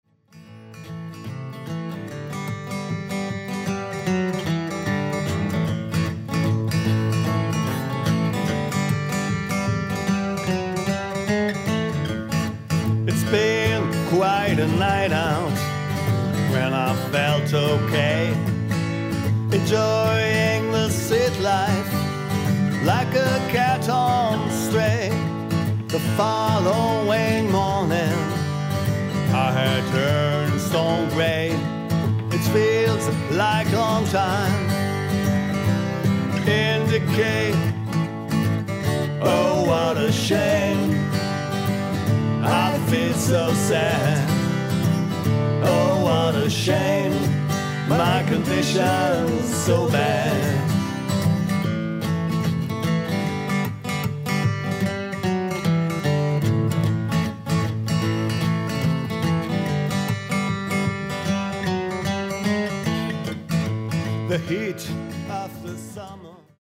boogiehafter Song